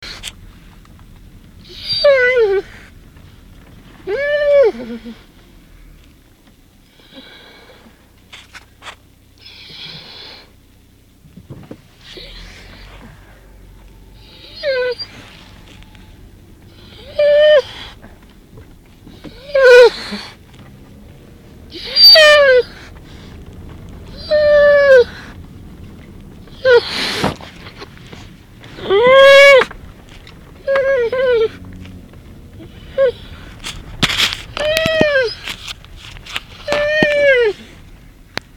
Field Recording – The Rudy Wimper
(A Chocolate Lab cries to go for a walk in the woods)